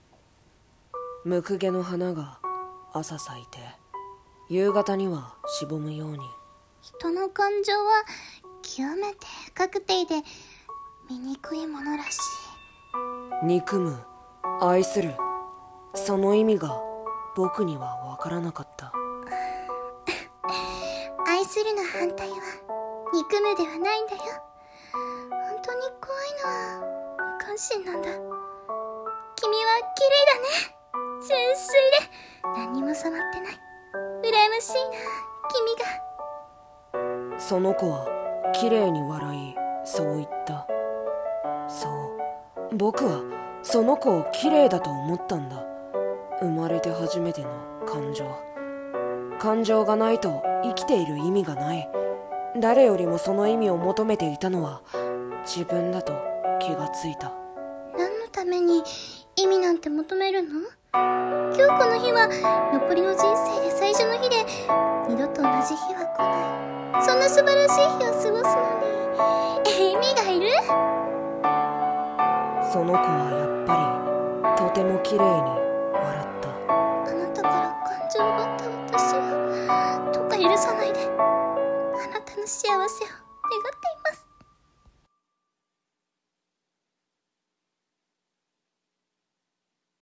恋蛍、泪に沈む。【掛け合い台本】